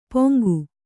♪ poŋgu